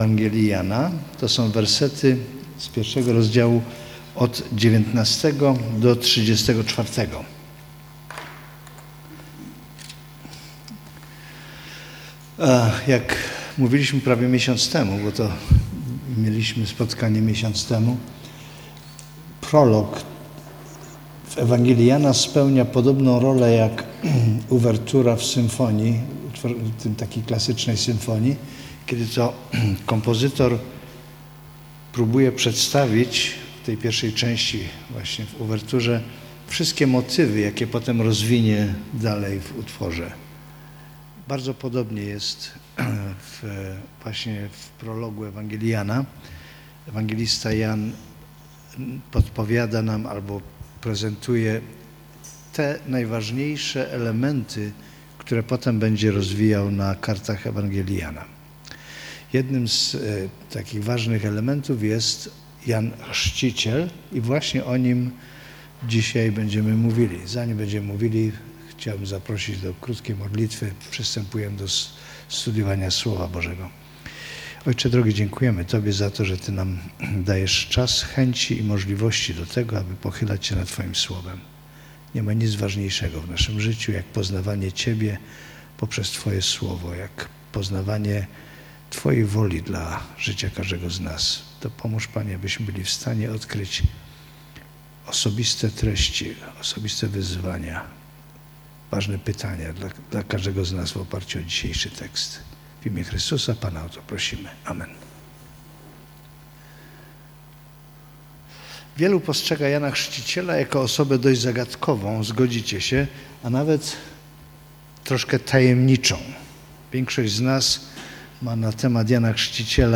19-34 Rodzaj Usługi: Spotkania z Biblią Jan Chrzciciel